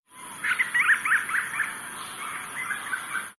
ʻAkeʻake (Band-rumped Storm-petrel) Call
cabbakecabbake-band-rumped-storm-petrel-call.wav